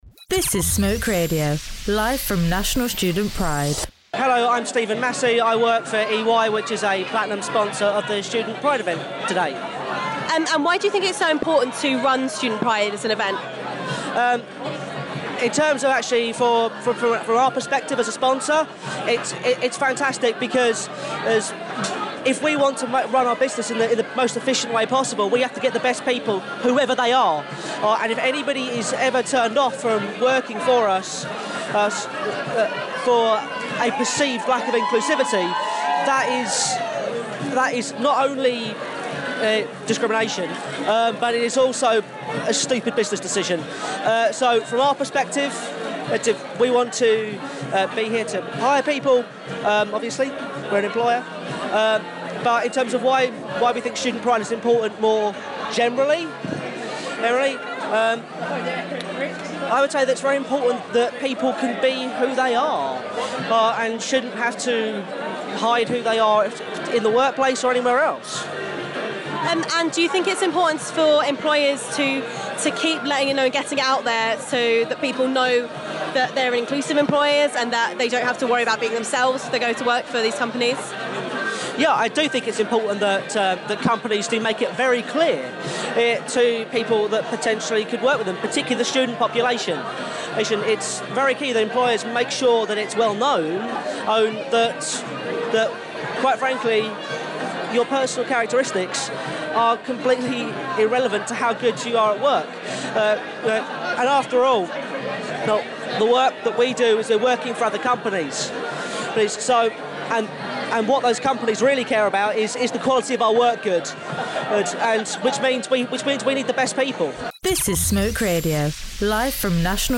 at National Student Pride 2017